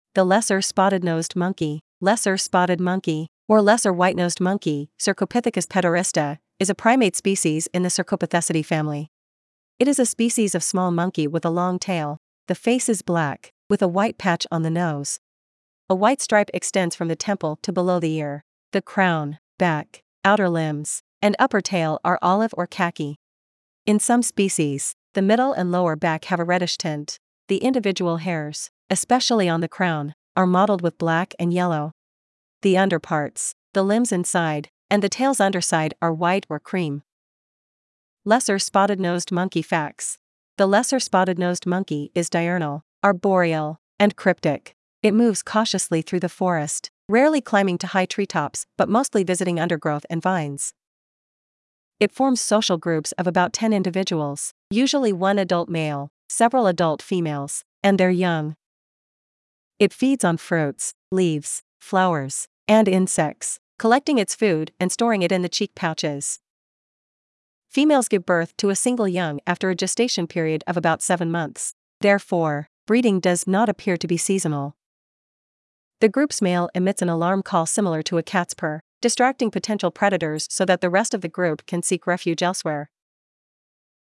Lesser Spotted-Nosed Monkey
• The group’s male emits an alarm call similar to a cat’s purr, distracting potential predators so that the rest of the group can seek refuge elsewhere.
Lesser-Spotted-nosed-Monkey.mp3